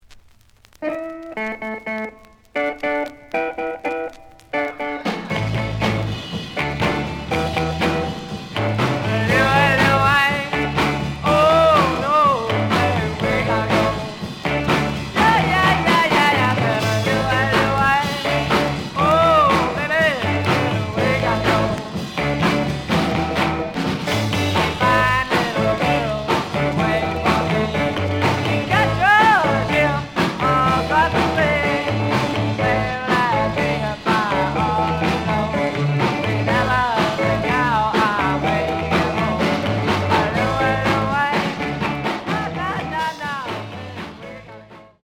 ●Genre: Rock / Pop
Slight edge warp.